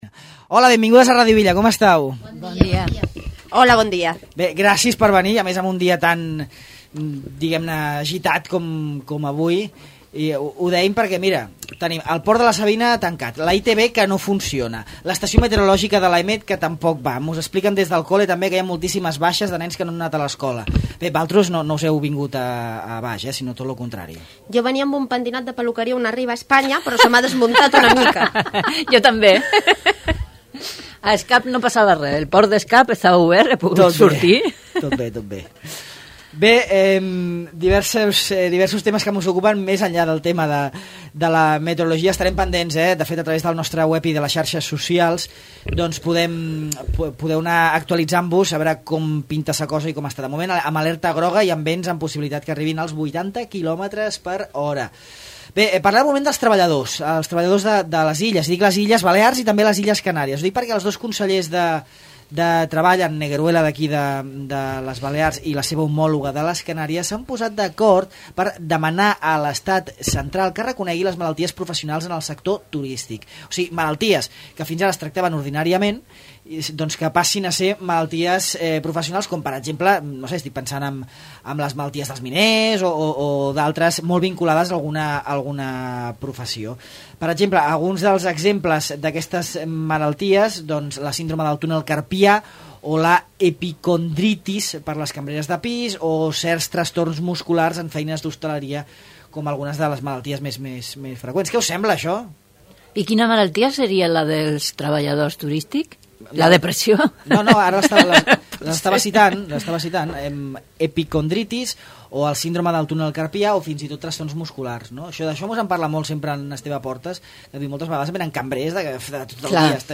La tertúlia dels Divendres